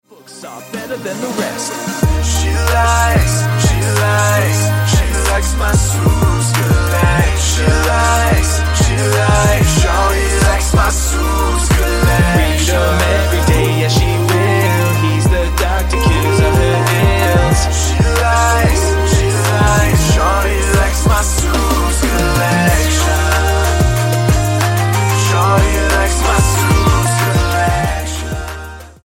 STYLE: Hip-Hop
Sung hooks and some clever production